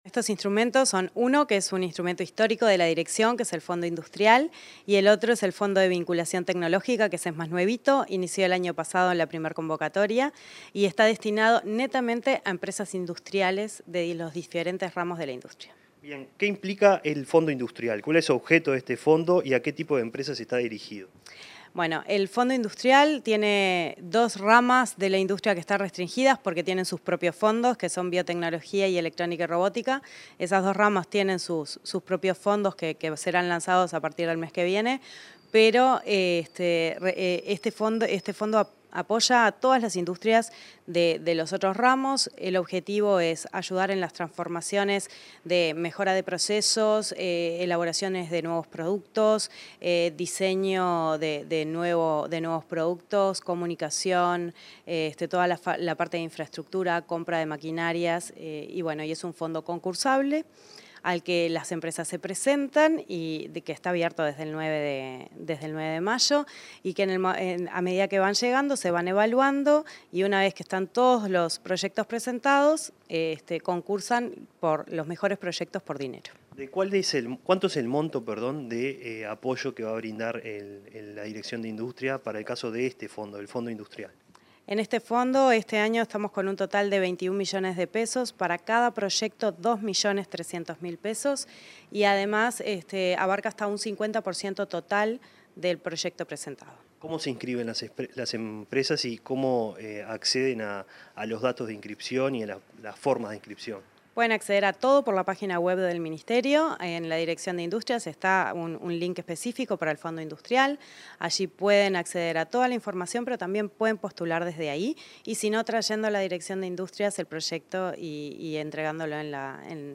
Entrevista a la directora nacional de Industrias, Susana Pecoy